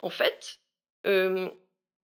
VO_ALL_Interjection_12.ogg